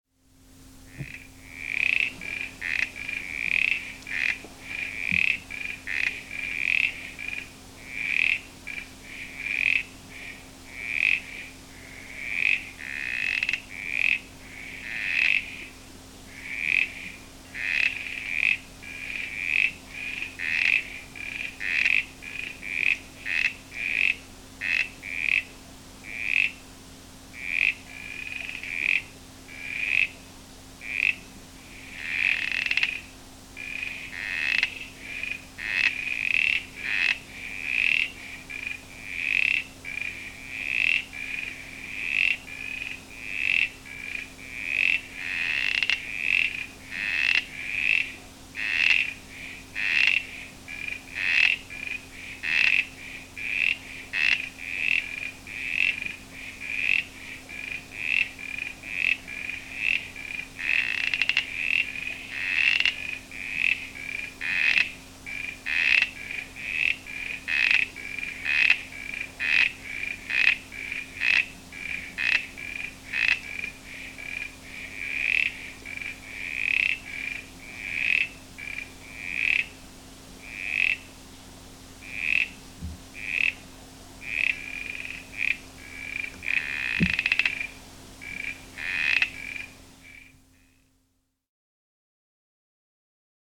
Frogs, Grampians, Easter 1980
Victoria Valley frogs
Frogs_Grampians_1980.mp3